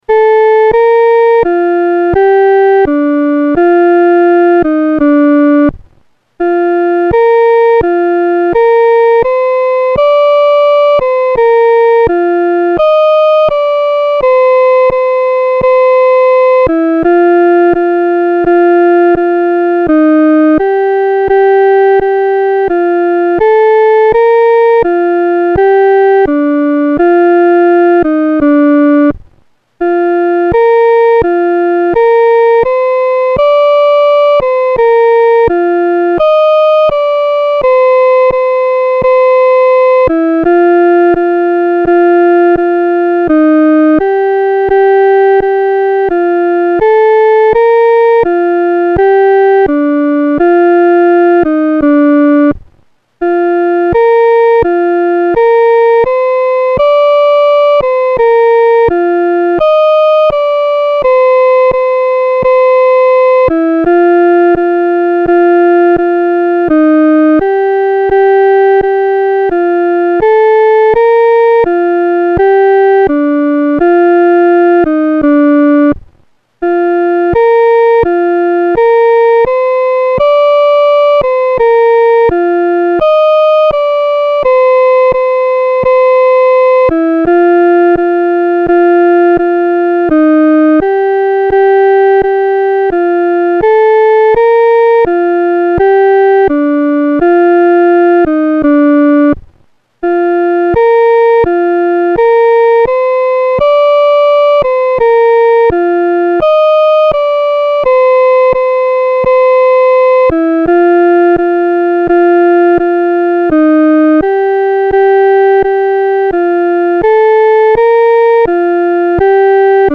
伴奏
女高